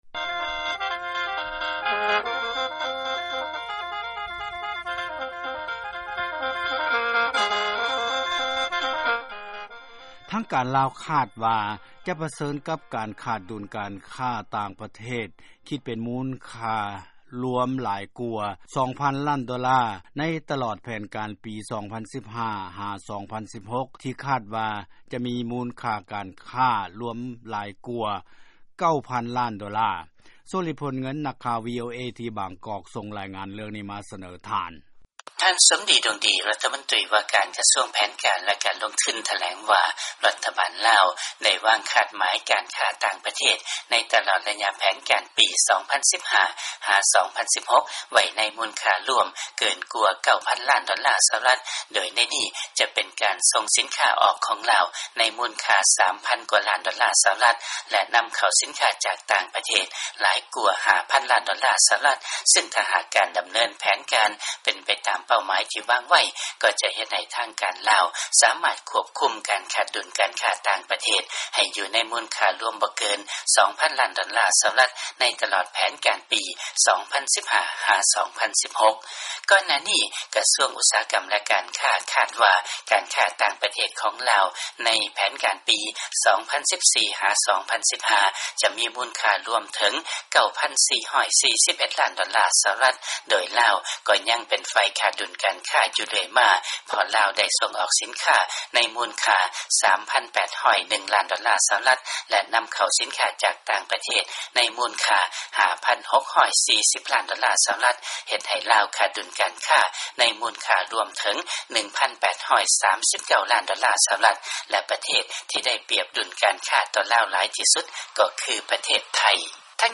ຟັງລາຍງານ ທາງການ ລາວ ຄາດວ່າ ຈະປະເຊີນໜ້າ ກັບການຂາດ ດຸນການຄ້ າຫຼາຍກວ່າ 2 ຕື້ໂດລາ ໃນປີ 2015-2016